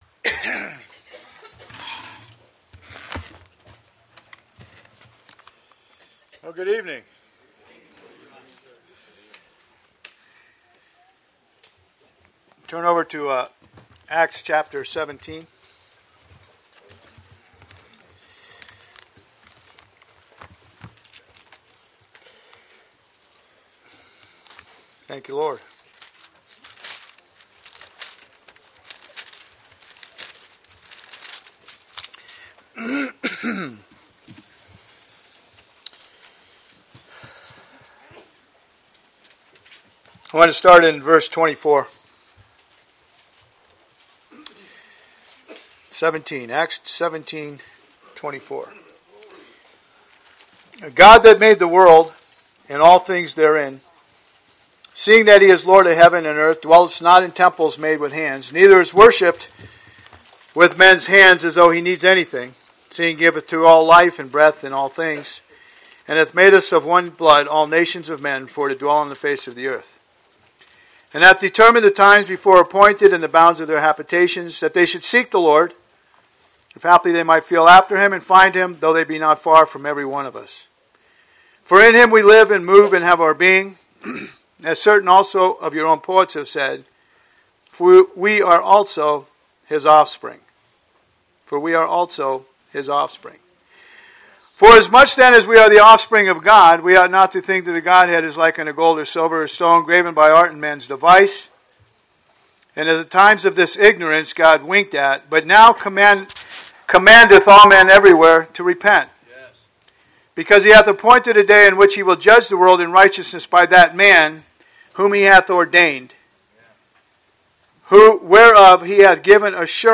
Posted in 2016 Shepherds Christian Centre Convention